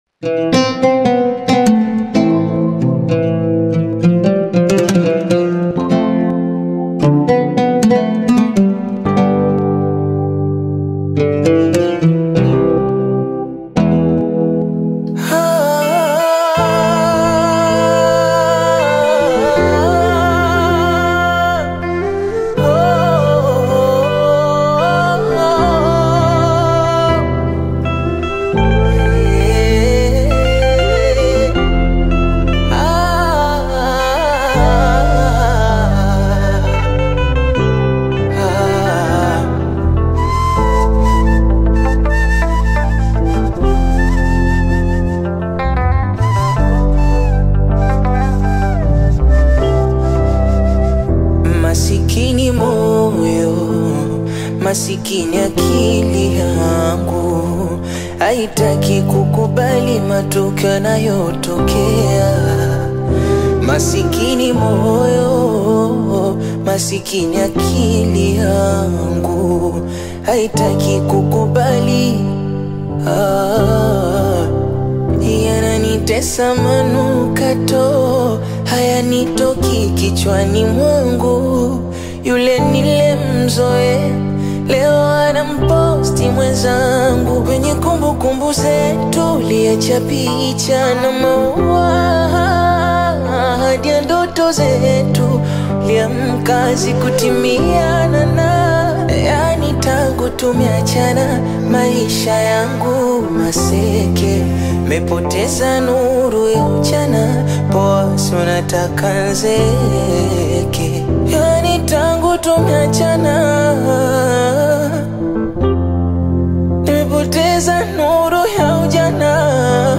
heartfelt Bongo Flava/acoustic rendition
Genre: Bongo Flava